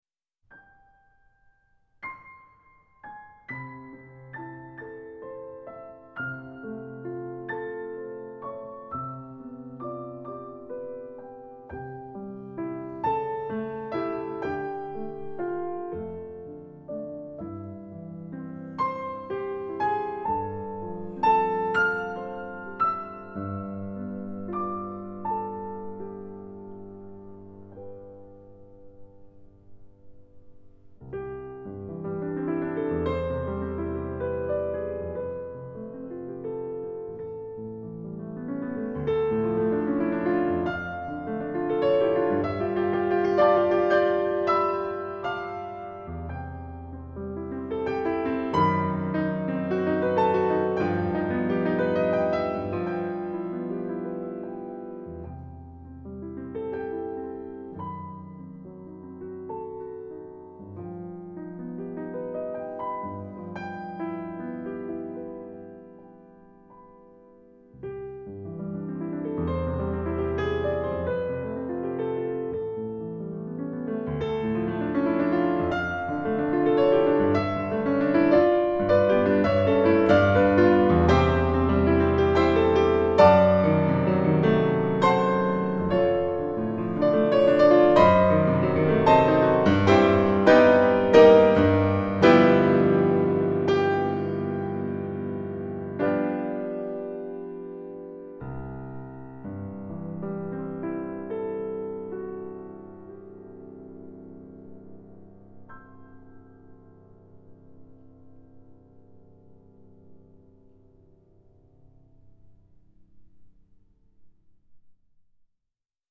Genre : Classical